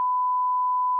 tone_1s_nosilence.mp3